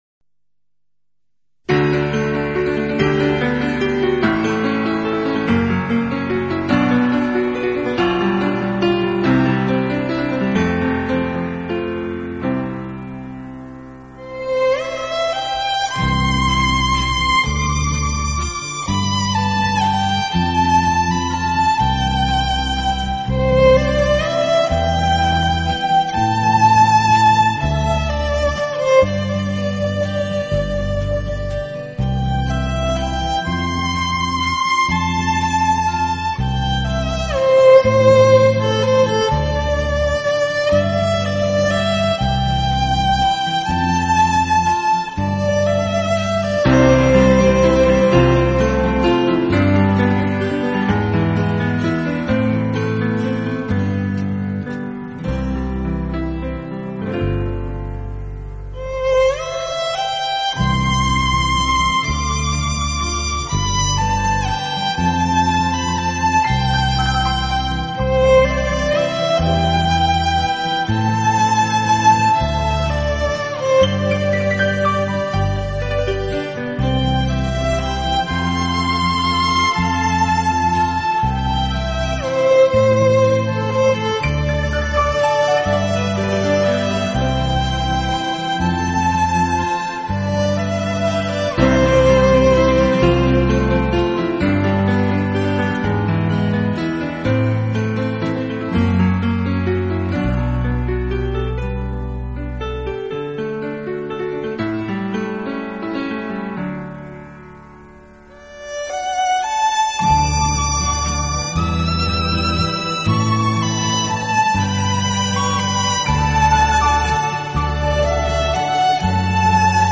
音乐类型：Instrumental 纯音乐